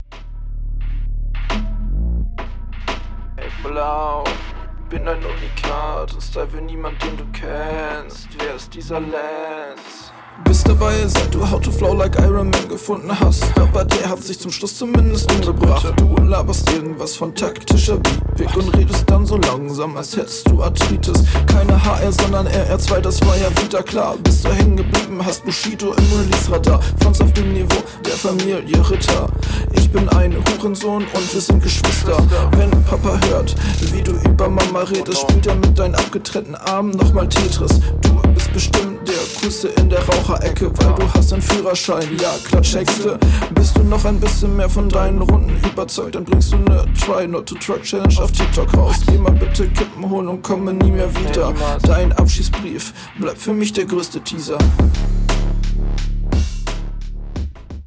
Mag hier auch wieder deinen Stimmeinsatz und finde du fährst einen echt coolen Style.